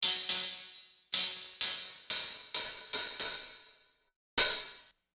blending over from the spectrum of the first hihat i found (it’s from the volca drum) to the 909, reference sample at the end …
(Personally I want my own hihats, catching the 909 vibe, not actually sounding like it …)